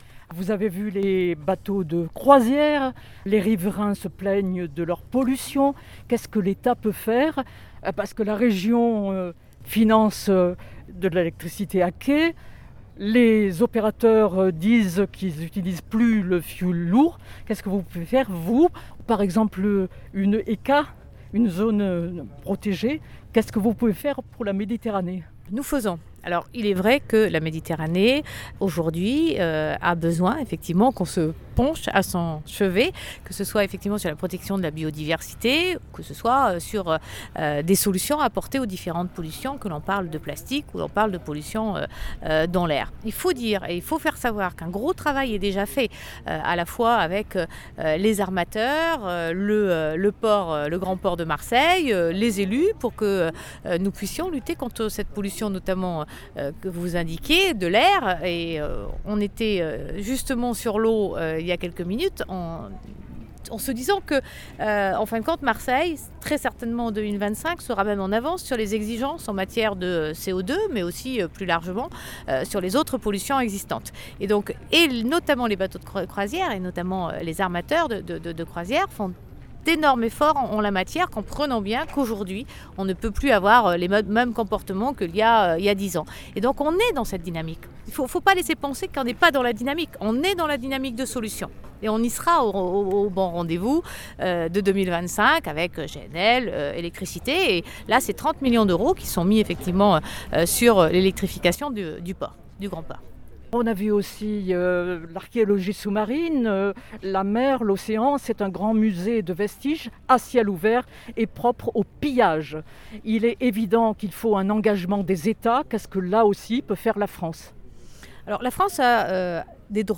Entretien avec Annick Girardin, ministre de la Mer